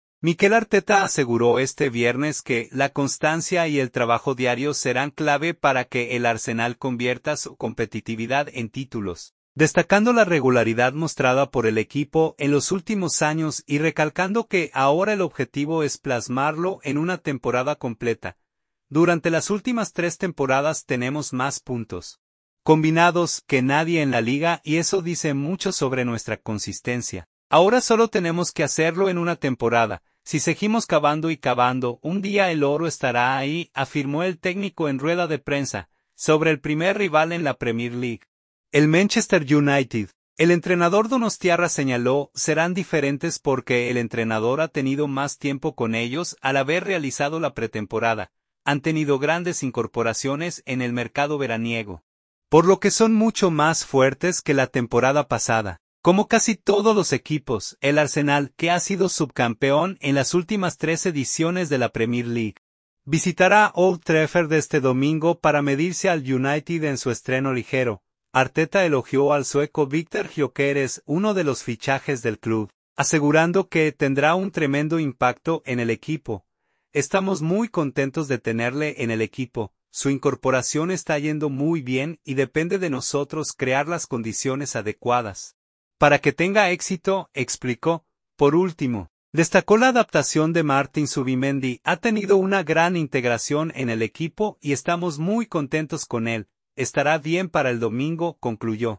“Durante las últimas tres temporadas tenemos más puntos -combinados- que nadie en la liga y eso dice mucho sobre nuestra consistencia. Ahora solo tenemos que hacerlo en una temporada. Si seguimos cavando y cavando, un día el oro estará ahí”, afirmó el técnico en rueda de prensa.